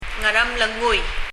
Ngaremlengui　という綴りをどうローマ字読みしても「アルモノグイ」にはならないのですが、ひょっとするとそう聞こえるかも、と３回発音してもらいましたが、「アルモノグイ」とは聞こえませんでした。
発音
もし、ムリヤリ　カナ表記するのでしたら、「(ガ）アラムレ（グ）ゥイ」あたりかな？